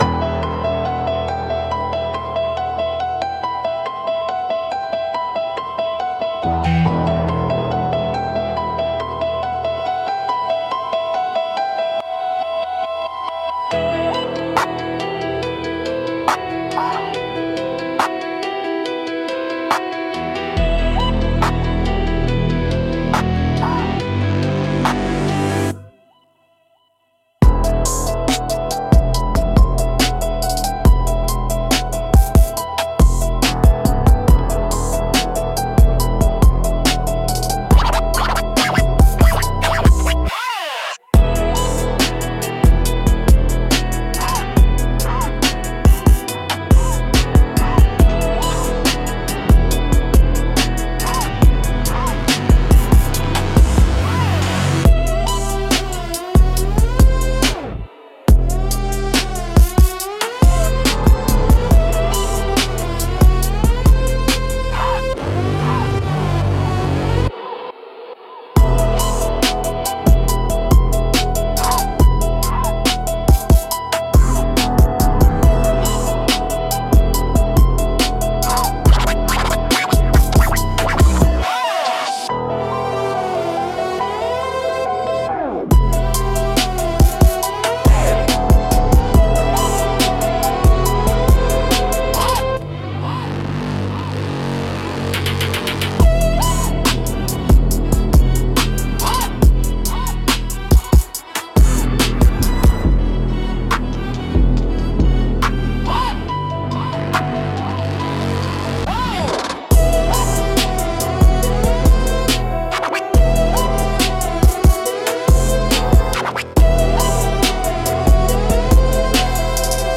Instrumentals - Choppin' at Shadows